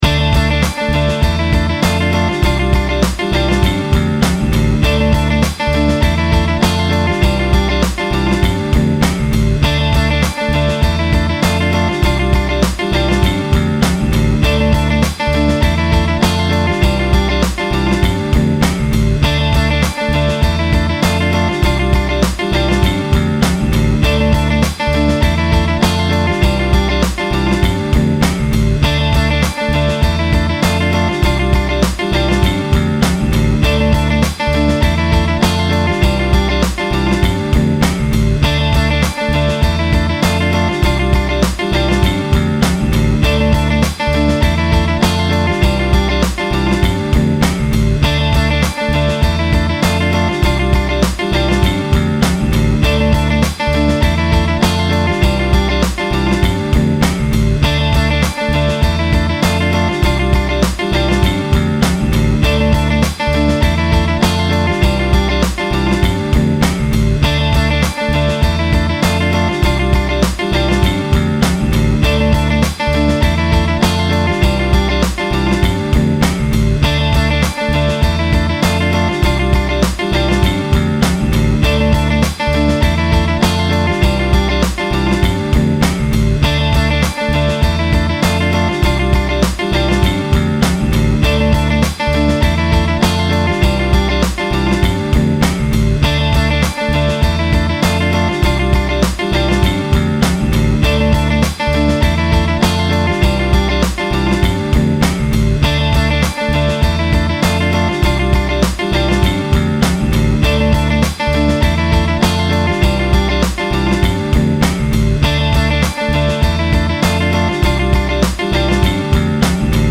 Slow Tempo: 100 bpmSlow Tempo: 100 bpm